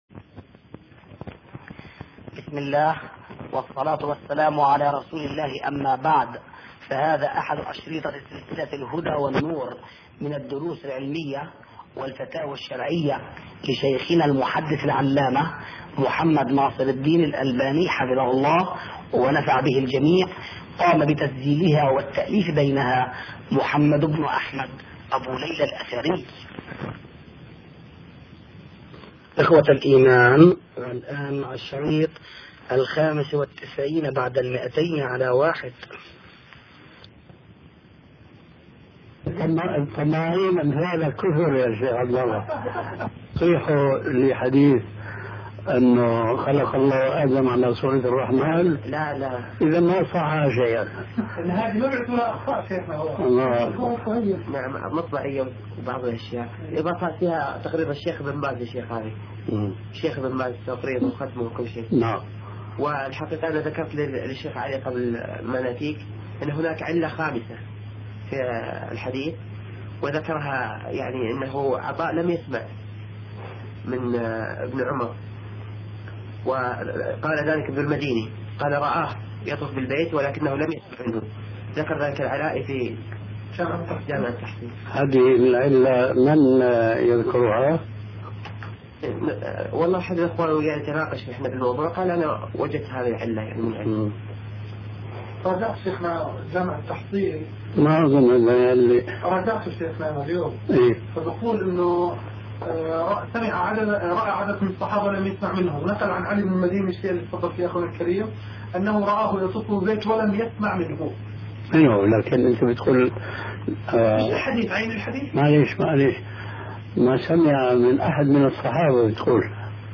شبكة المعرفة الإسلامية | الدروس | خلق الله آدم على صورته |محمد ناصر الدين الالباني